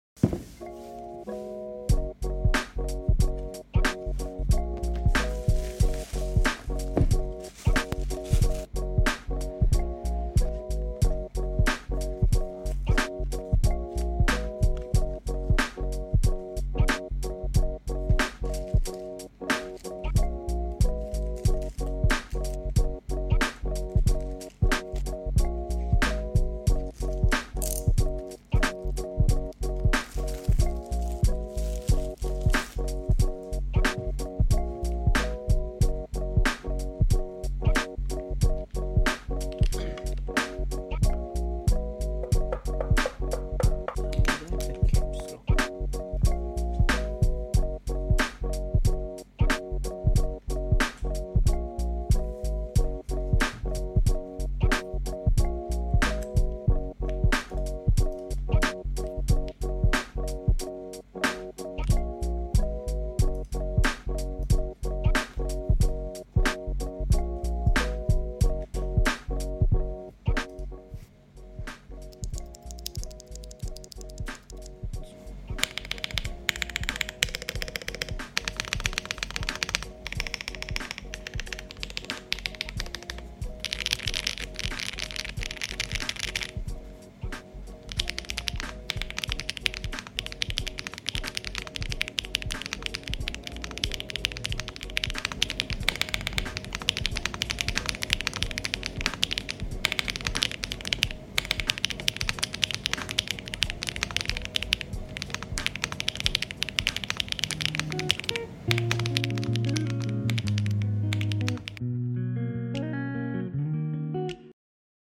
Keyboard alumunium 75% dengan switch bawaan HMX Lemon Grape dimana HMX terkenal dengan smoothness dan konsistensinya in stock. keycapsnya PBT Double shot dengan printing yg konsisten.